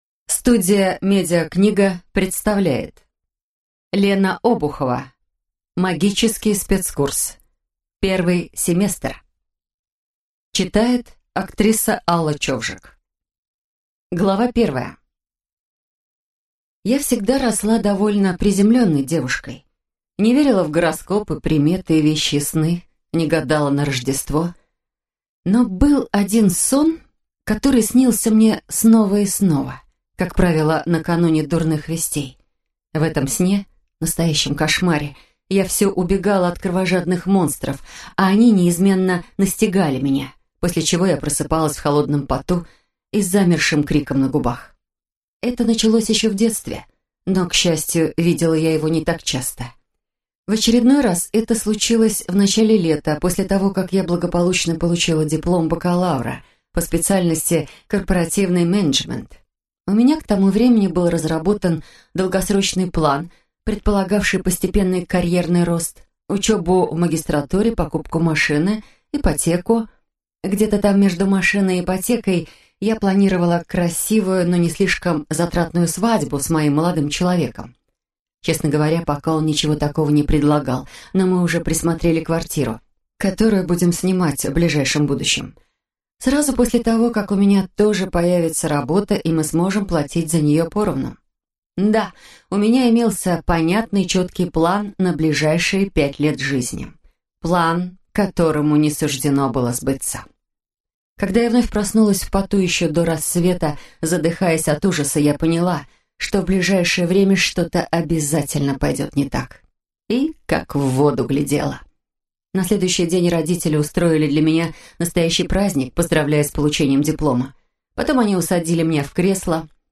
Аудиокнига Магический спецкурс. Первый семестр | Библиотека аудиокниг